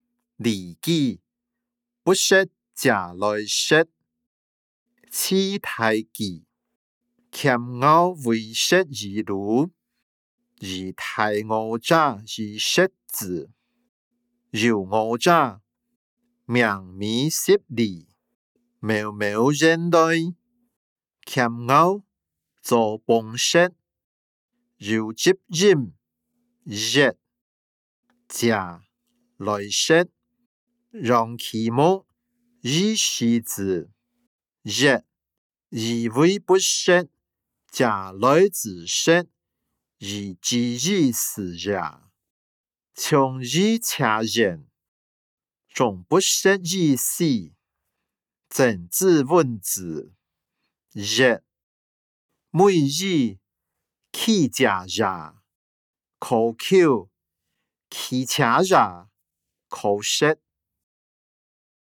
經學、論孟-不食嗟來之食音檔(饒平腔)